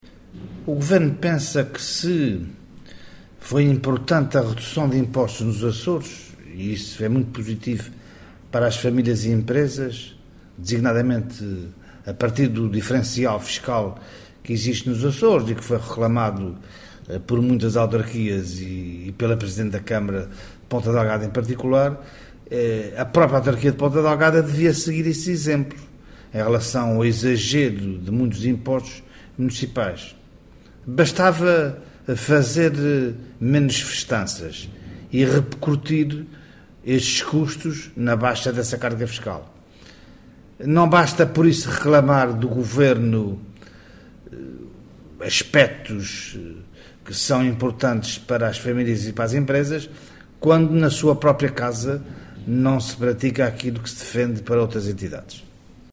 O Secretário Regional da Ciência, Tecnologia e Equipamentos, que presidiu à sessão solene do 10º aniversário de elevação da Ajuda da Bretanha a freguesia, elogiou a importância e a vitalidade do poder local, afirmando que apesar de os “tempos serem difíceis”, isso não pode representar a paralisação da atividade das juntas de freguesia, nesse sentido, “há sempre parceiros que têm noção das suas responsabilidades e garantem ajudas e apoios concretos”.